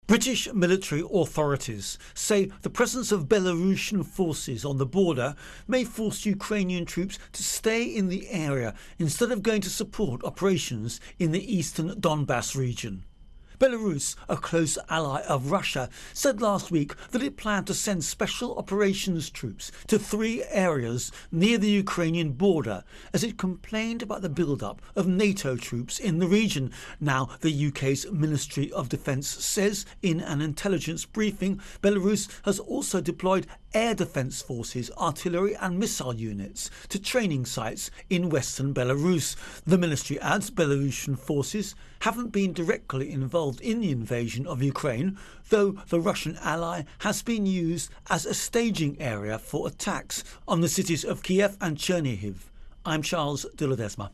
Russia Ukraine War-UK-Belarus intro voicer